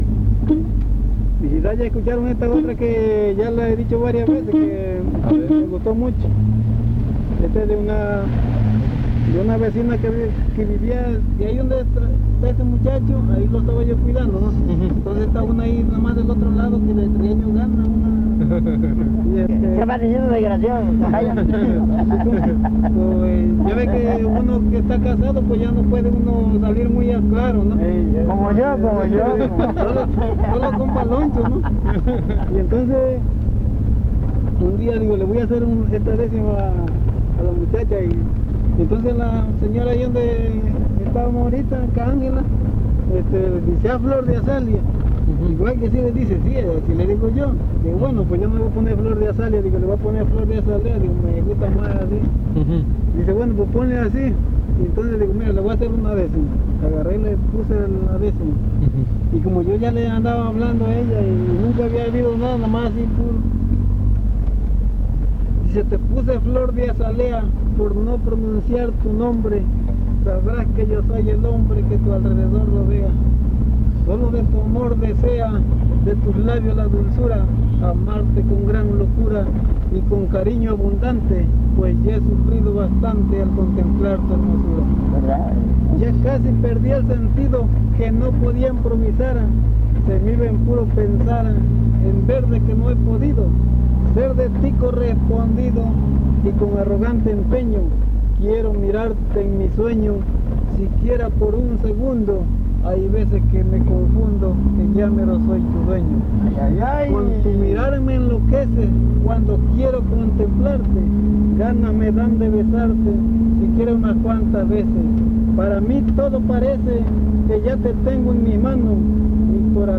Fiesta de Santiago Tuxtla : investigación previa